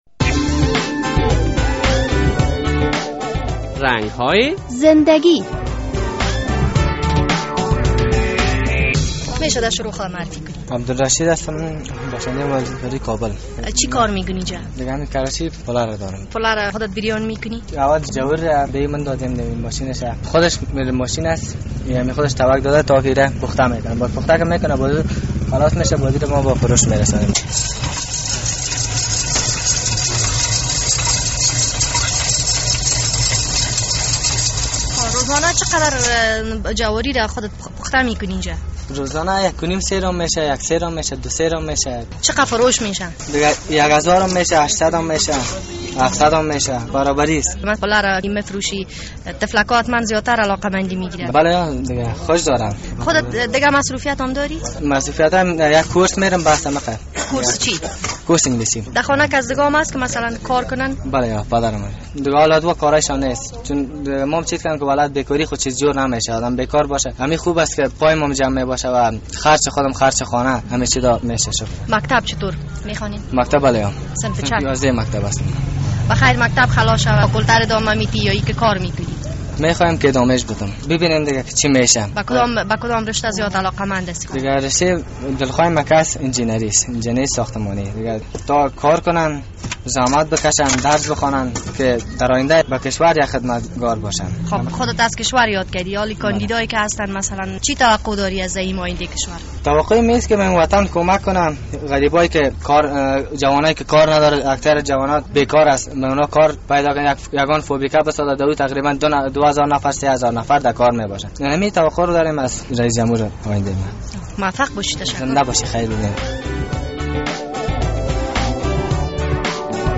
مصاحبهء کوتاه با یک پُله فروش در کابل